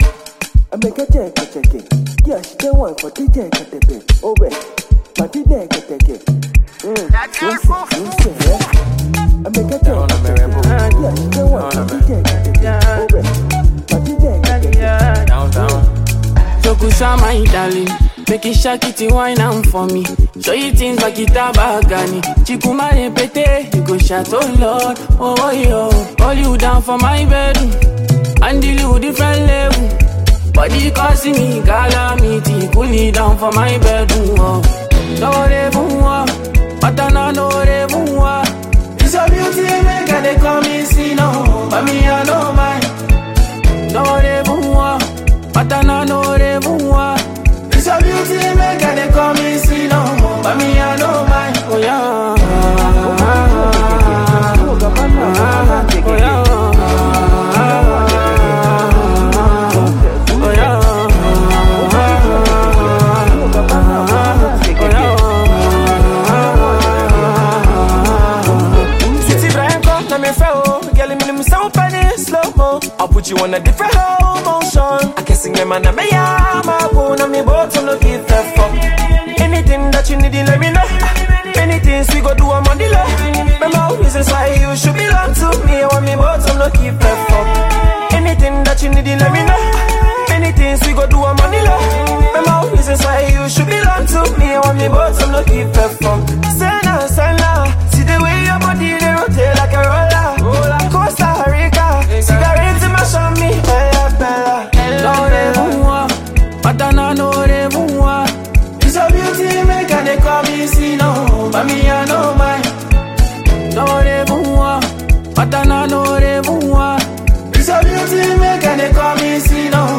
Afrobeat singer